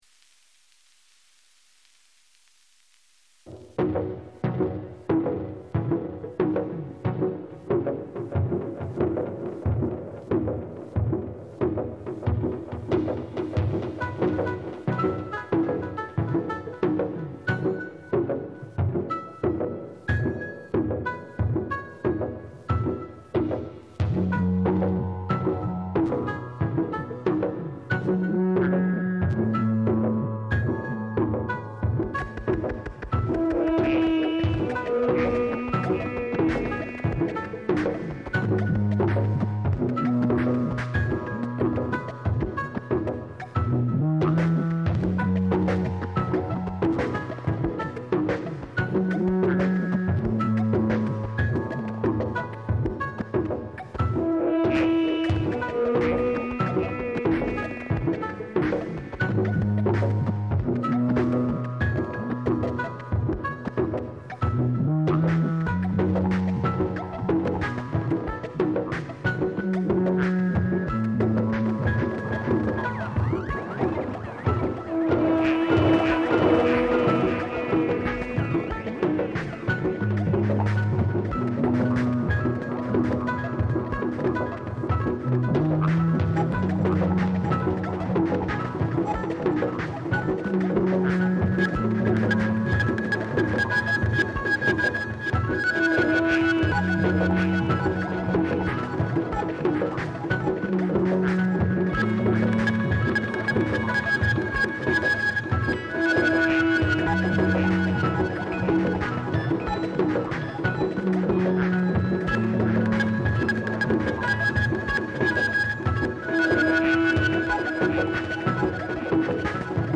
it took a bit of time to get my bits configured (e.g. finding/choosing samps, configuring mlre et al to work how i wanted it to work etc) specifically because it was my first real dig into using the norns as well as the mlrevariant of mlr. the biggest hurdle throughout this whole process was myself. i kept having to ignore the self-conscious voice telling me to start over after a mistake or not to start at all because family was awake.